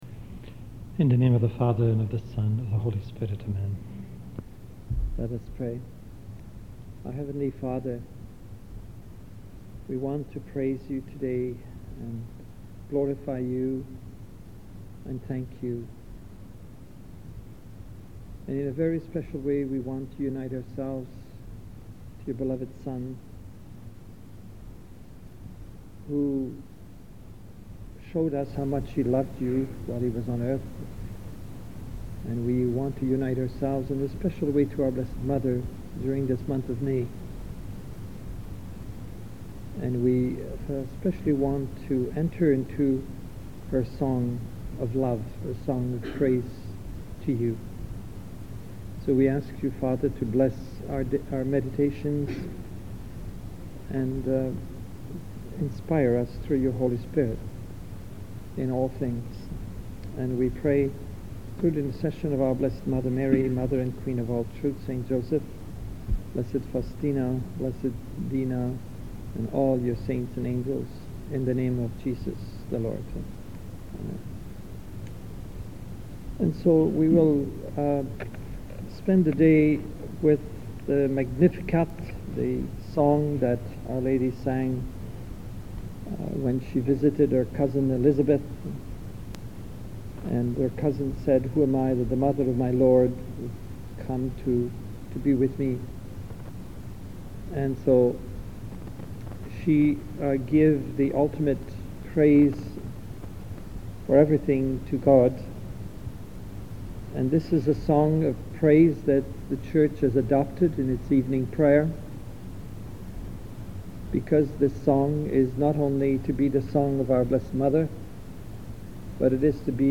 Magnificat Talk on the Magnicat Given on a silent retreat in 2009